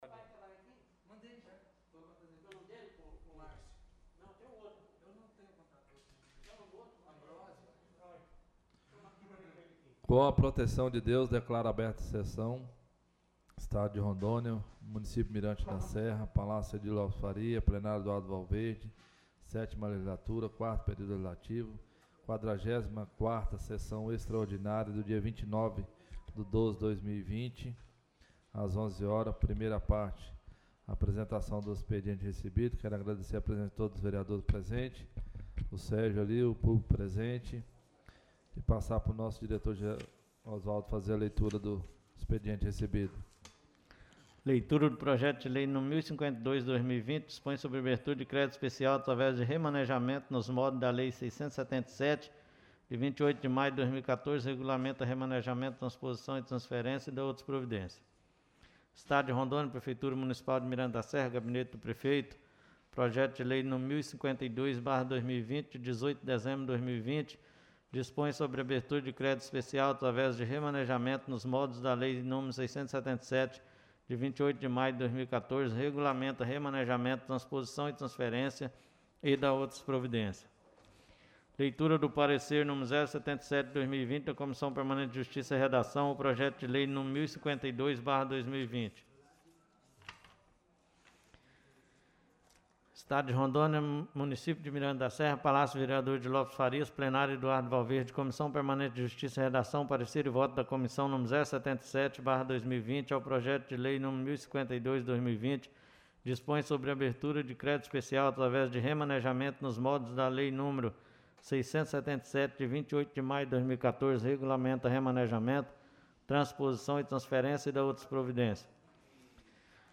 44ª Sessão Extraordinária da 27ª Sessão Legislativa da 7ª Legislatura
Sessão Extraordinária